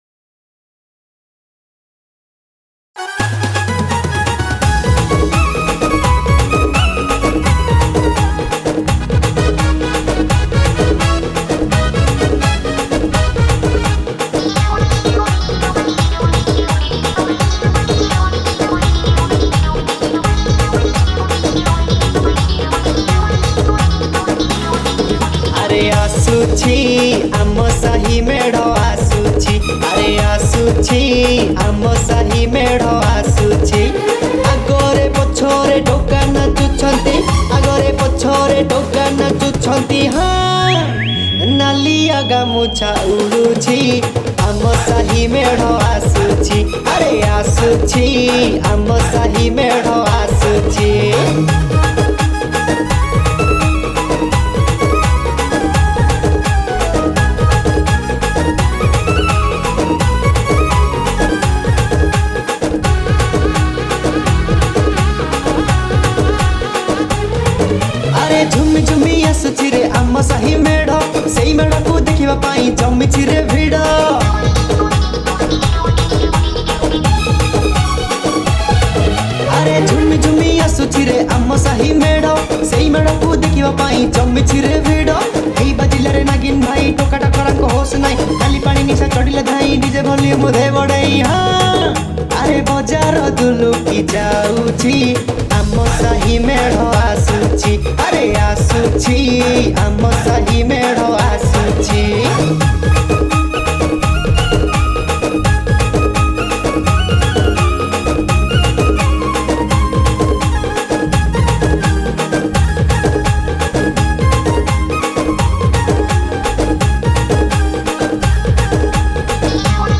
Ganesh Puja Special Mp3 Song Songs Download
KEYBOARD